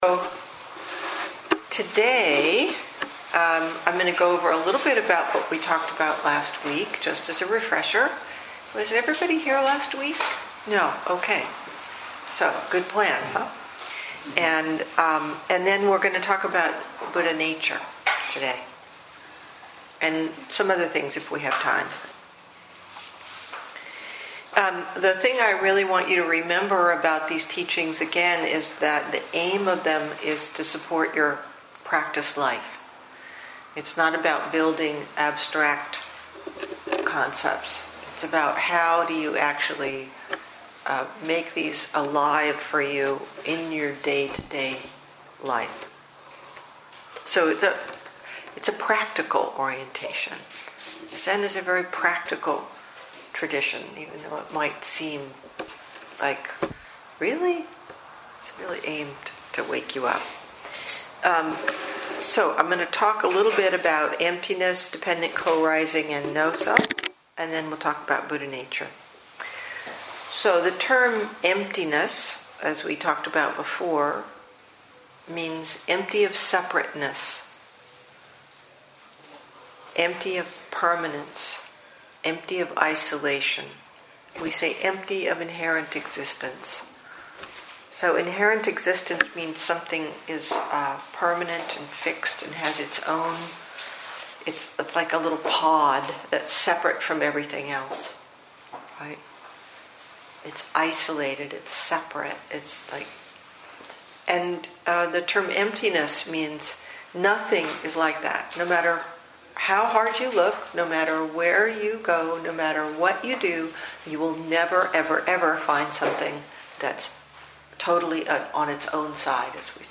2017 in Dharma Talks